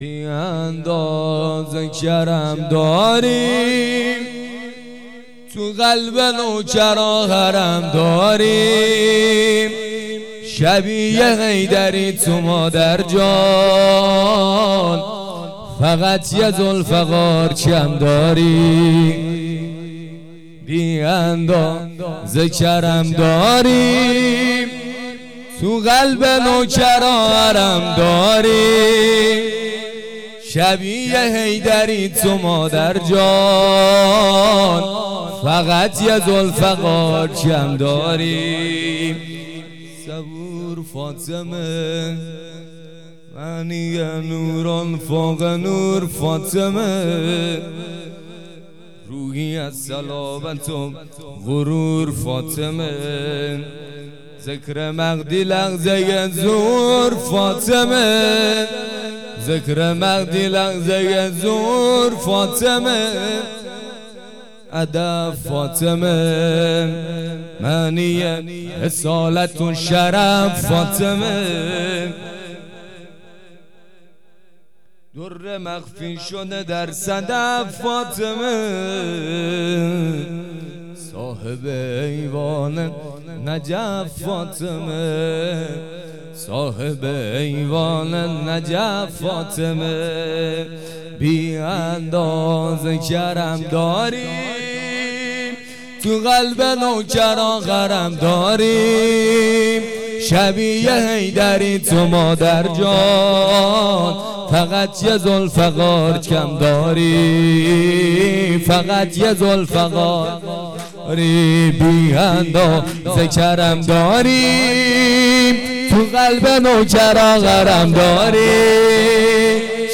سرود
شب ولادت حضرت زهرا ۹۹